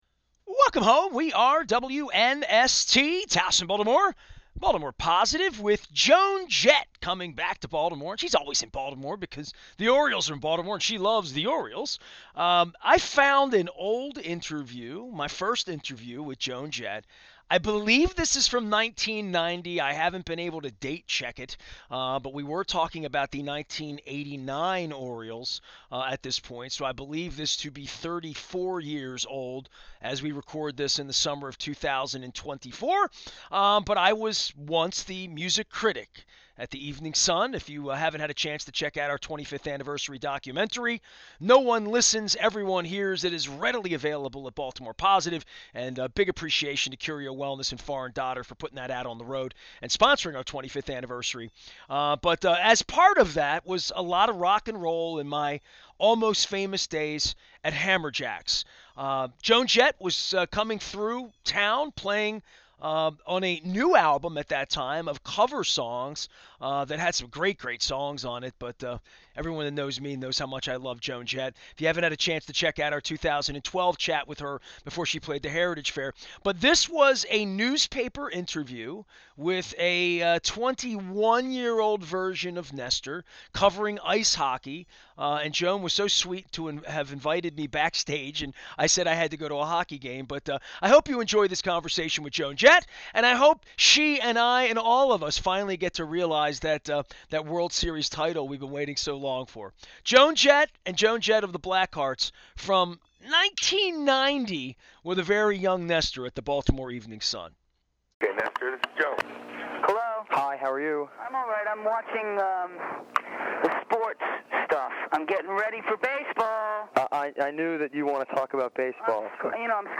This is a long lost chat with Joan Jett that oozes her love of the Baltimore Orioles and Memorial Stadium.